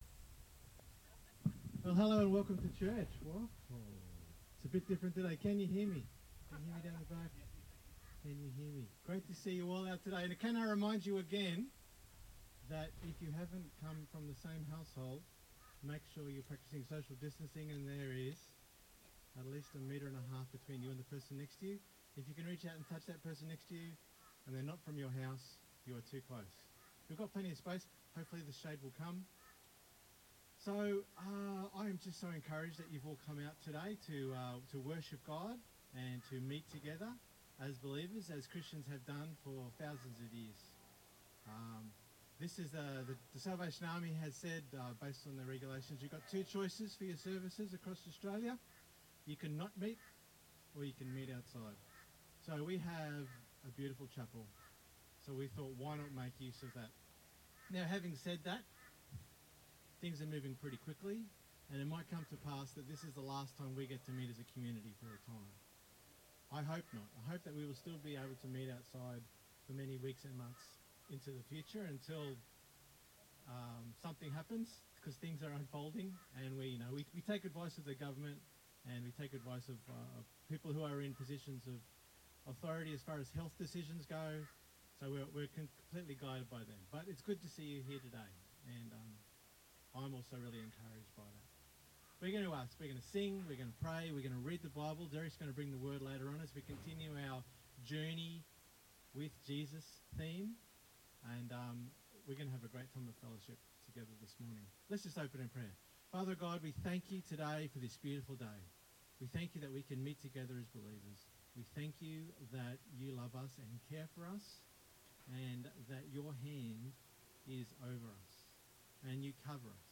This is the entire service in AUDIO FORMAT to enable you to hear what may happen to our services over the coming weeks. Sorry for the Audio levels you will need to adjust levels from time to time. Readings are Psalm 33:16-22 John 9:1-7